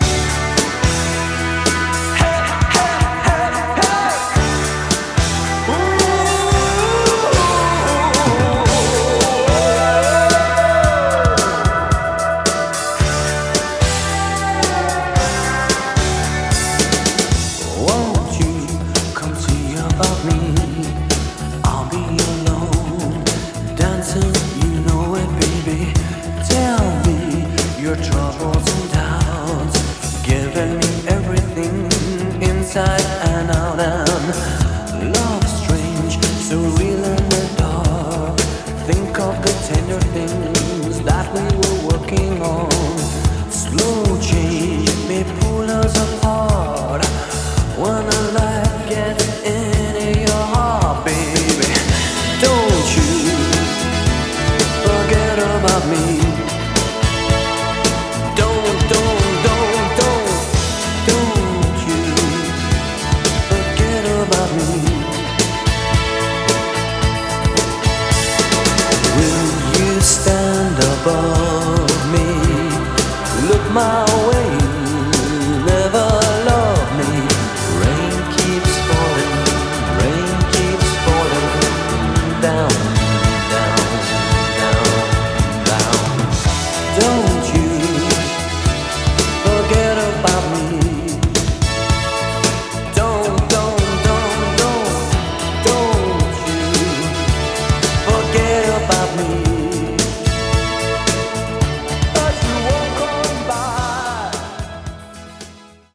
BPM111
Audio QualityMusic Cut
BRIT POP